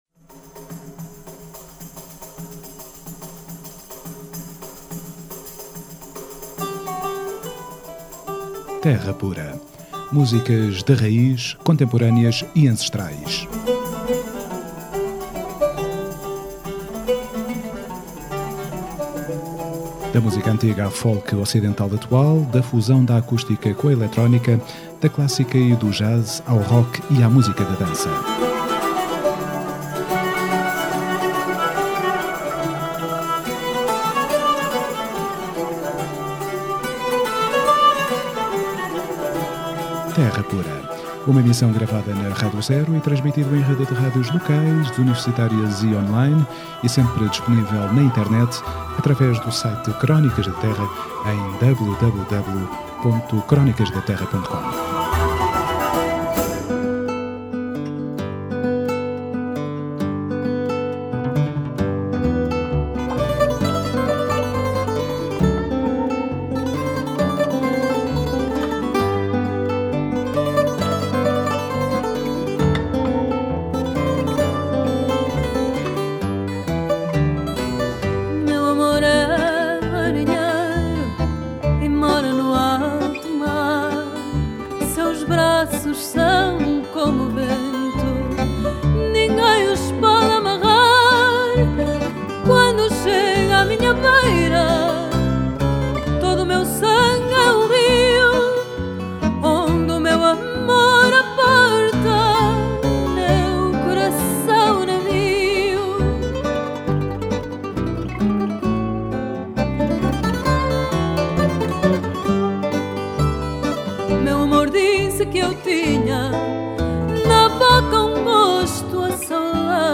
Terra Pura 14FEV14: Entrevista Cristina Branco